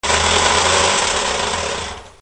Trapano, avvitatore, utensile elettrico
Suono di attrezzo elettrico rotante.